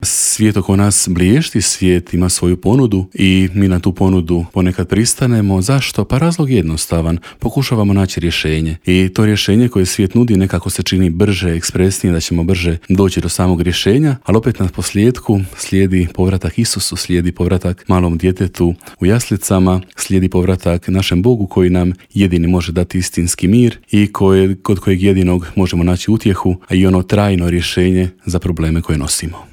Intervjuu